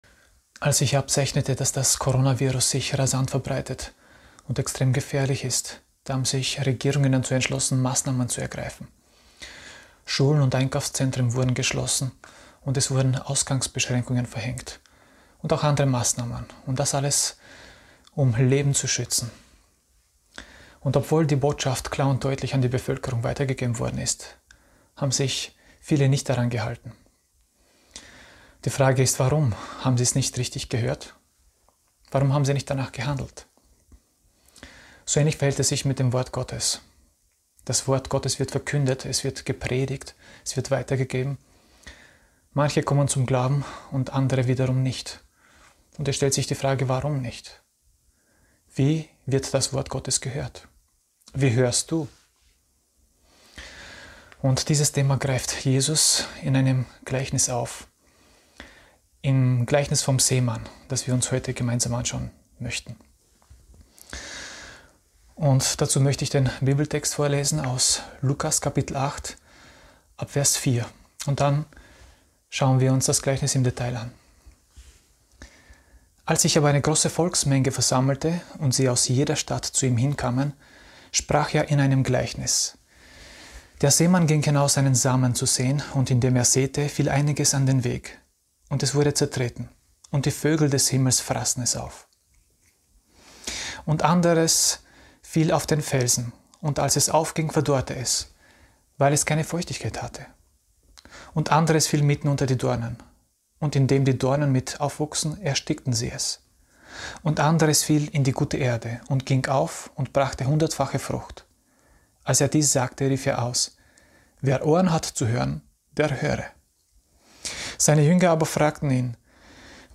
Wir werden Jünger Passage: Luke 8:4-15 Dienstart: Sonntag Morgen %todo_render% Wie hörst du?